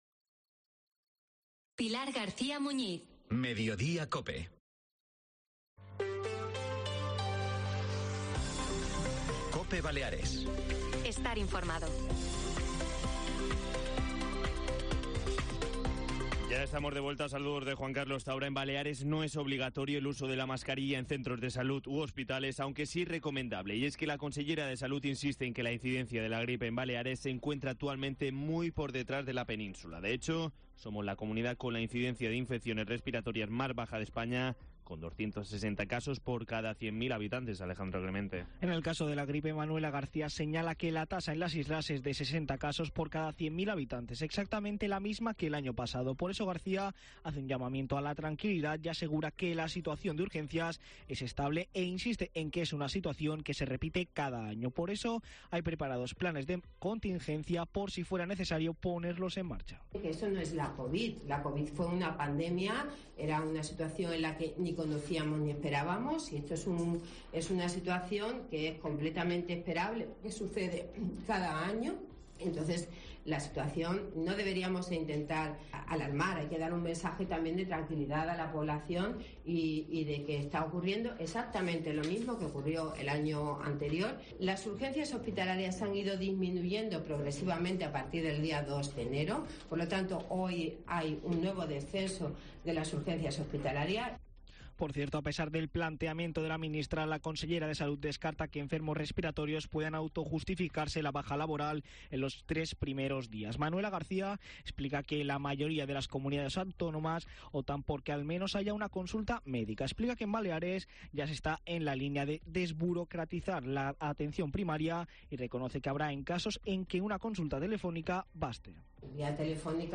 AUDIO: Preguntamos a los ciudadanos si estarían o no de acuerdo con la vuelta de mascarillas en centros de salud u hospitales.